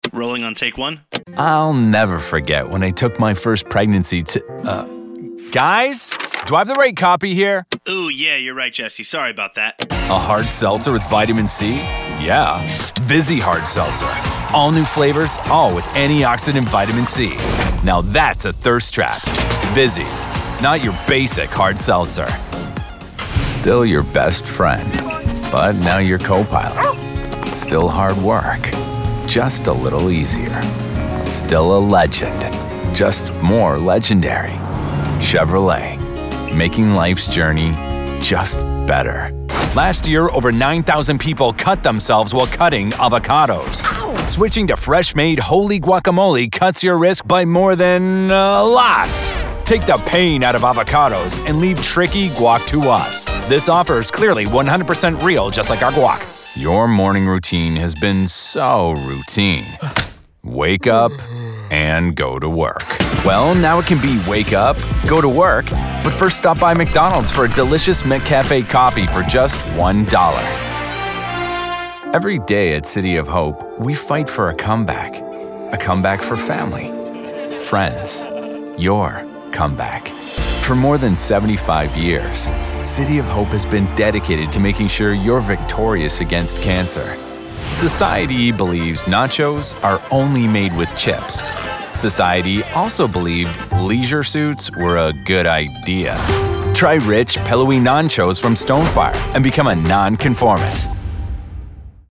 Commercial Demo
Standard American, New York, California, Canadian West Coast.
Friendly, conversational, guy next door
Genuine, believable, authentic
High-energy, hard sell (when you need that extra push)